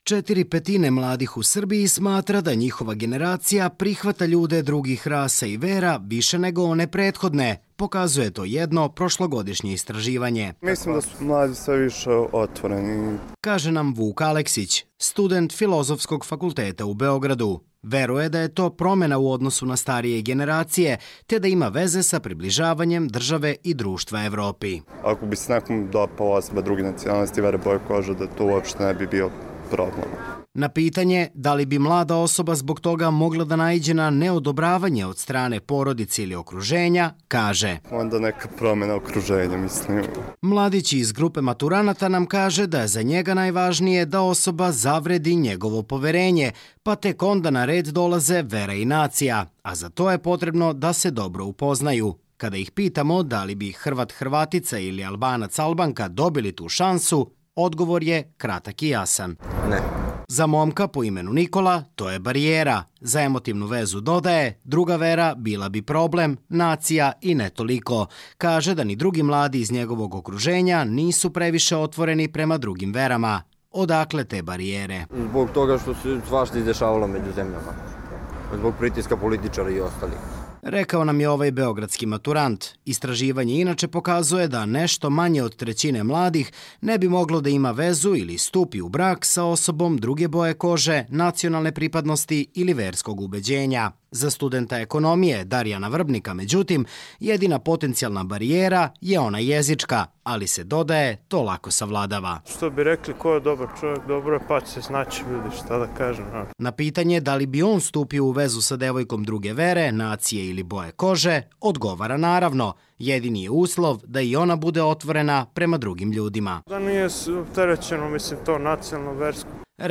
nekoliko studenata Beogradskog univerziteta